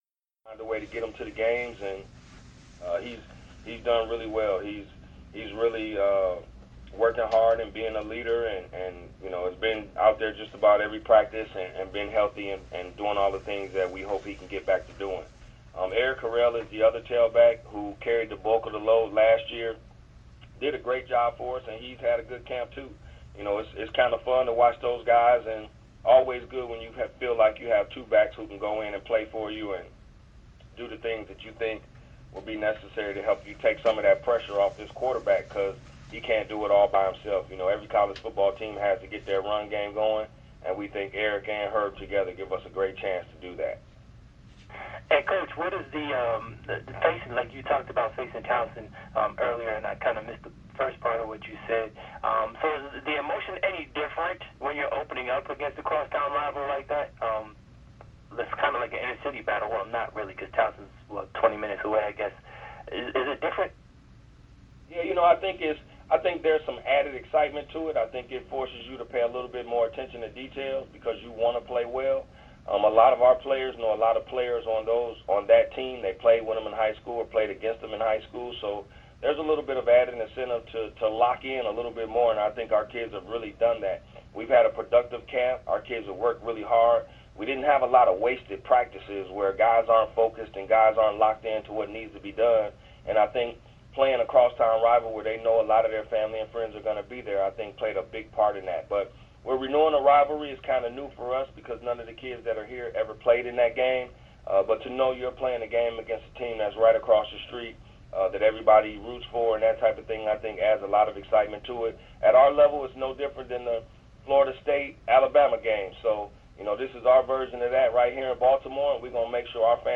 MEAC Teleconference Audio  |    Video Icon GAMEDAYGuide   |